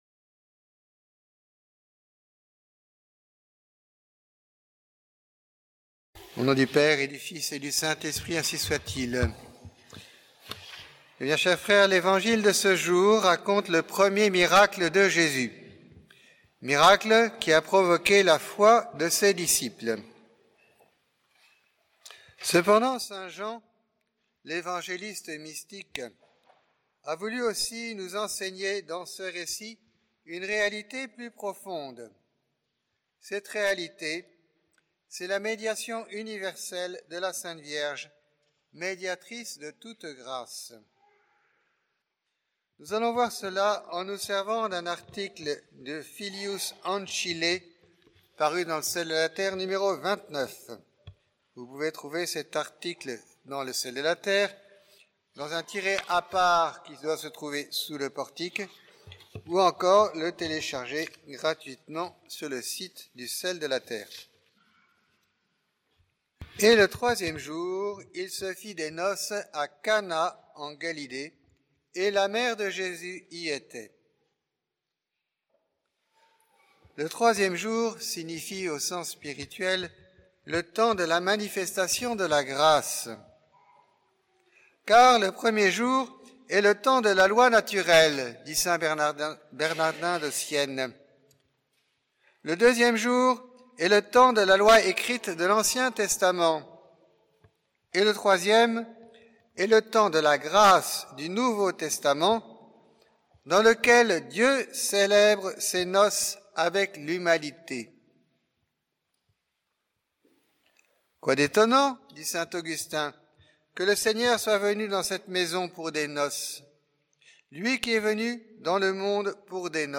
Occasion: Deuxième Dimanche après l’Épiphanie
Type: Sermons